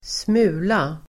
Uttal: [²sm'u:la]